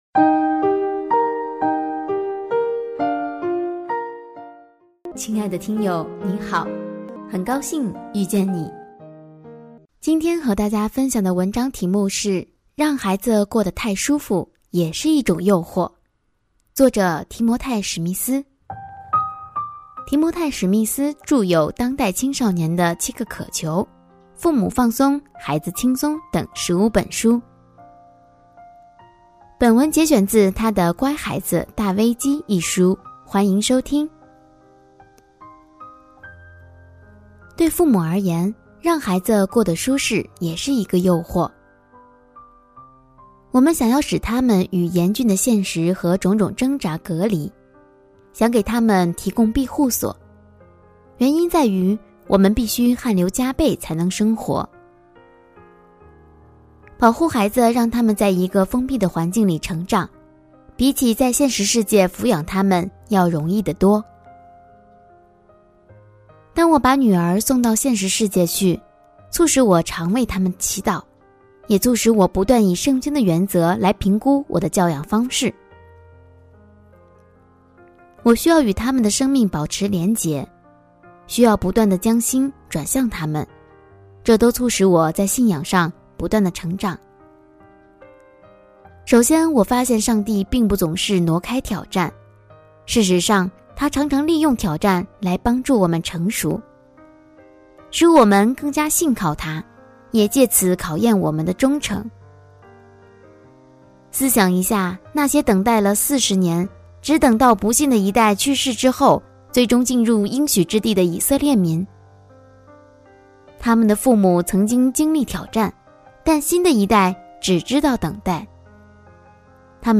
首页 > 有声书 > 婚姻家庭 > 单篇集锦 | 婚姻家庭 | 有声书 > 让孩子过得太舒服，也是一种诱惑